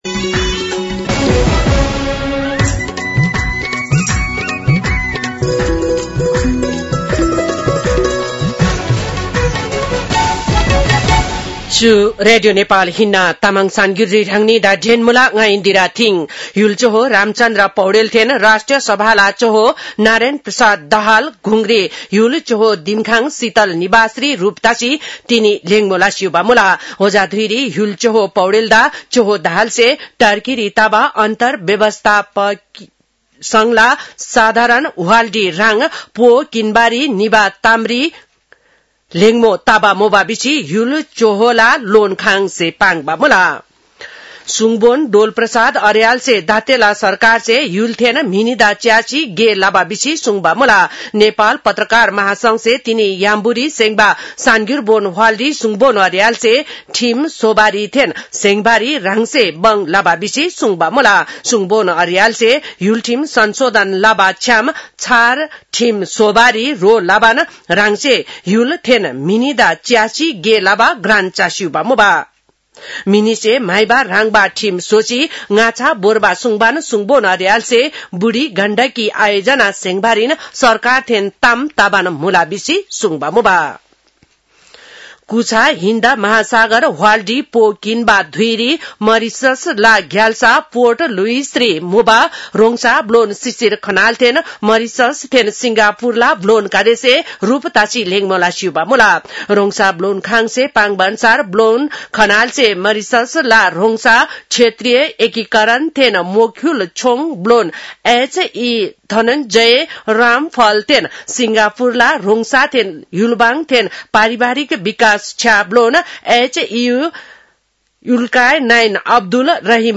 तामाङ भाषाको समाचार : २९ चैत , २०८२